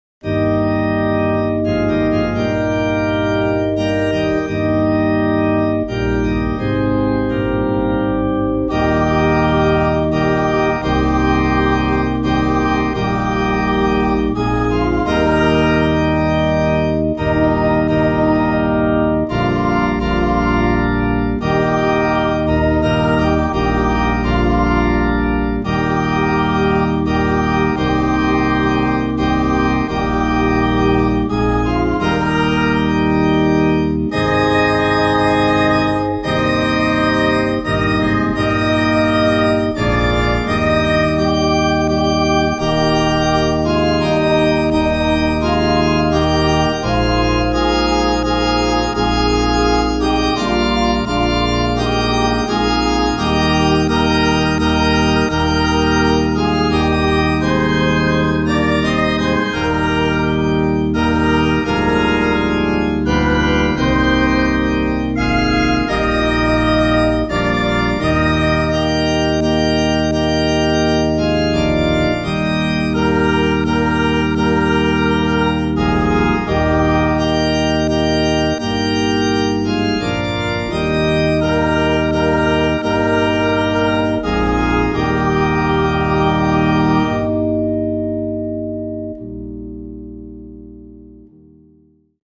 For my hamfisted backing I entered the accompaniment, made it into a MIDI file and then fed that file back into BIAB to get the chords and added the melody line.